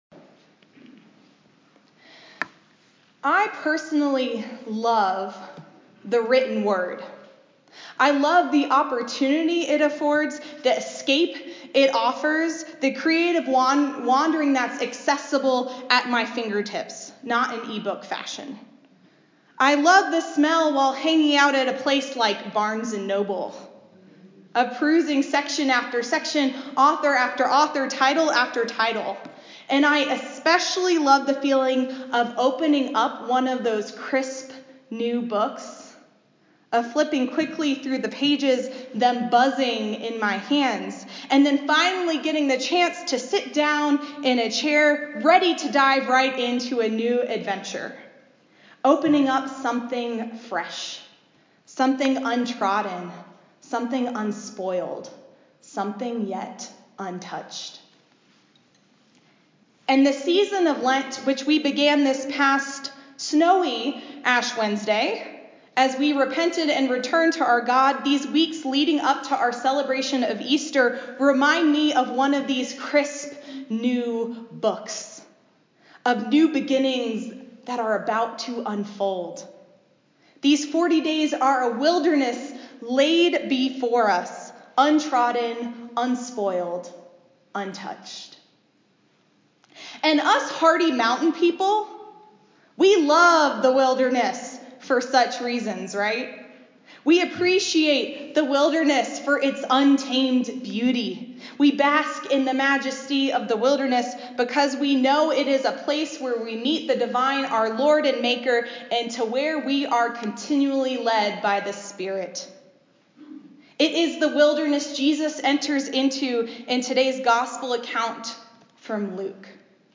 a sermon for the First Sunday in Lent, Year C